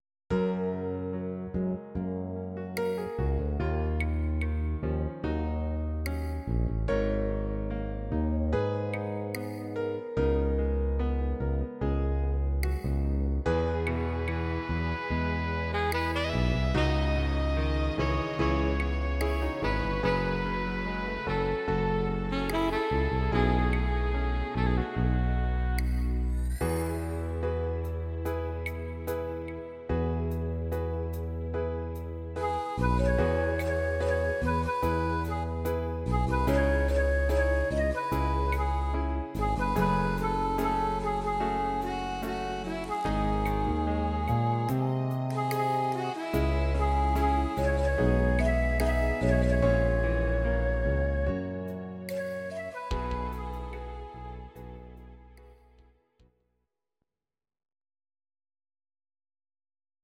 Audio Recordings based on Midi-files
Ital/French/Span